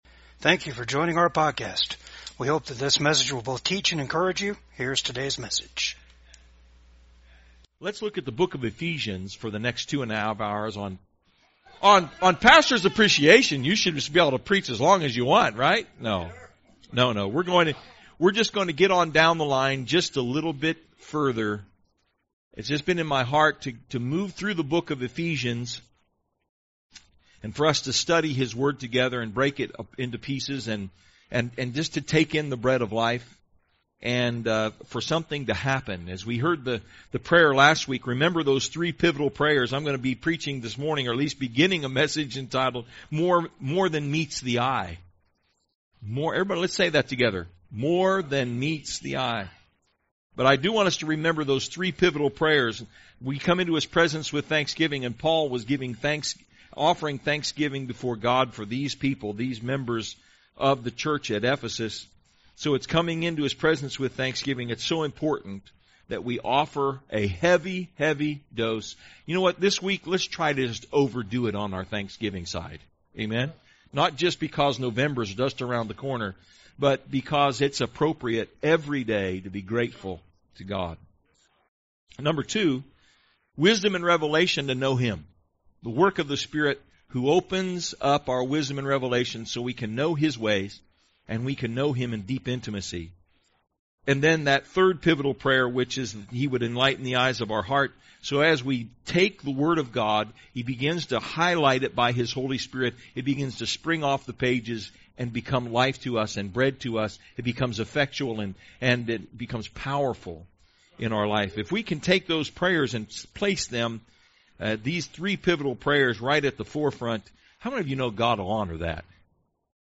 Ephesians 2:1-7 Service Type: VCAG SUNDAY SERVICE There is more than meets the eye...